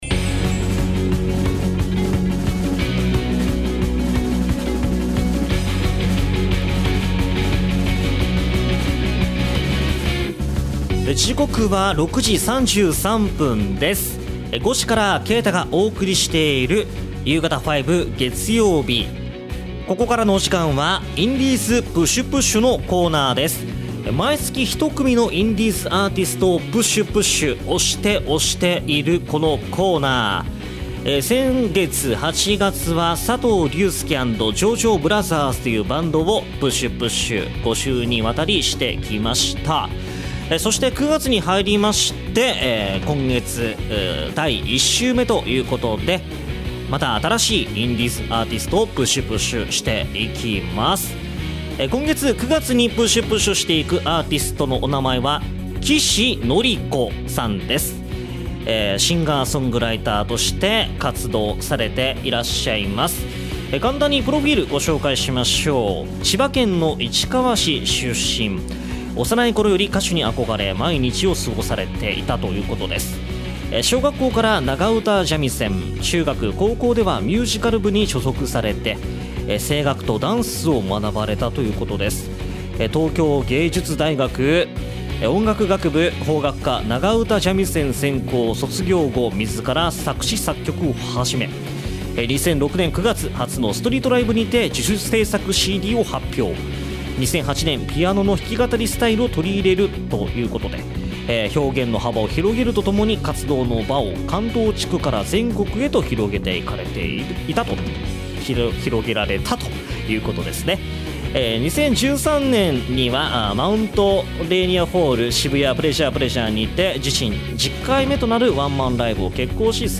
今週の放送同録音源はこちら↓